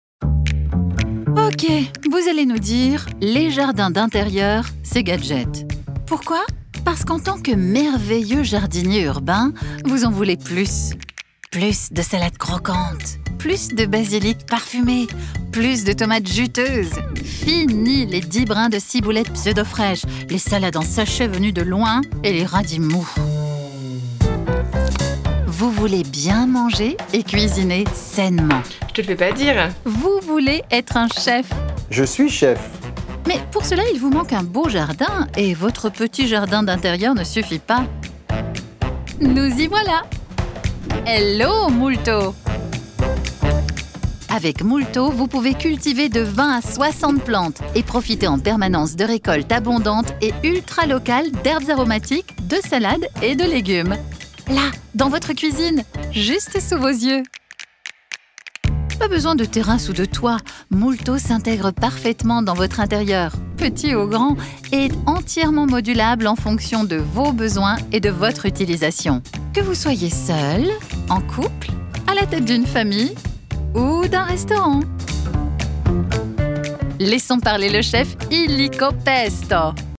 Voix Off Pub Pétillante Crowdfunding Multo
Avec Multo, Prêt à Pousser réinvente le potager d’intérieur en le rendant modulable, innovant et ultra productif. Cette campagne Kickstarter pleine d’audace a pour objectif de révolutionner nos habitudes alimentaires, et j’ai eu l’honneur de prêter ma voix pétillante et malicieuse pour guider les contributeurs dans cet univers verdoyant.
Mon rôle ? Une narration vivante et malicieuse, pensée pour captiver l’audience et transmettre la passion derrière ce projet.